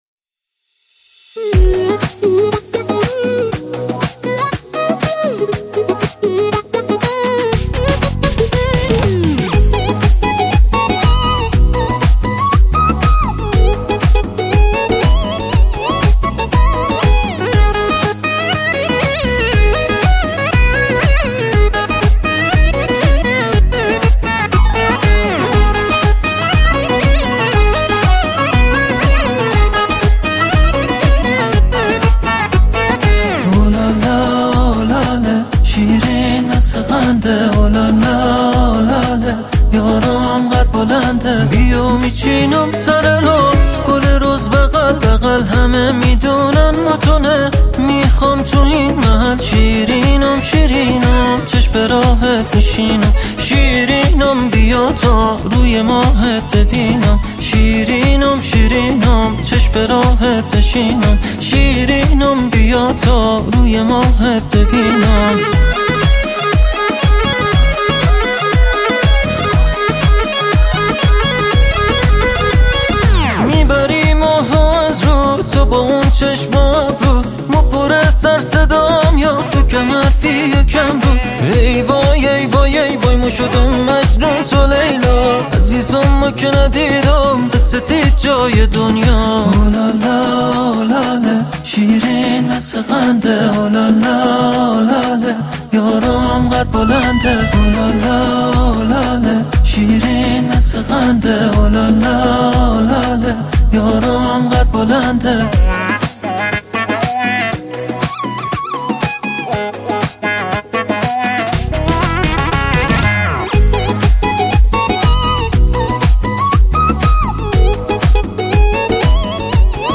او خواننده موسیقی پاپ میباشد.